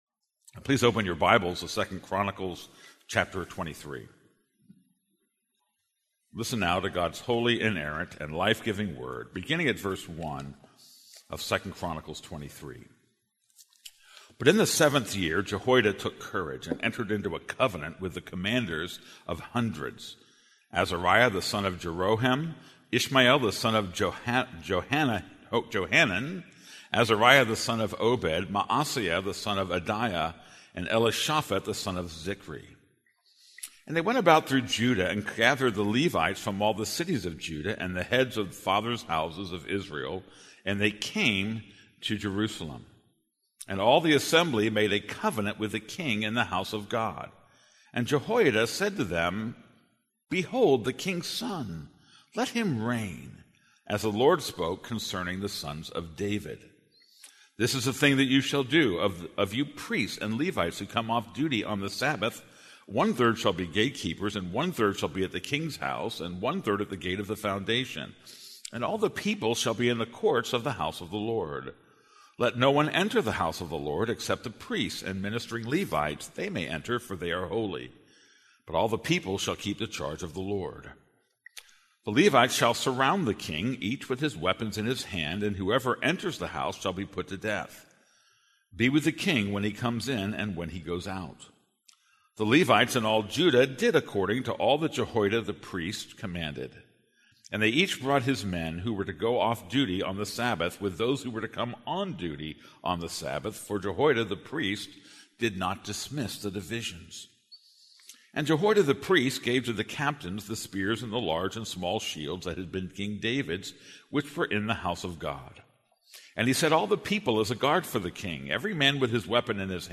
This is a sermon on 2 Chronicles 23:1-15.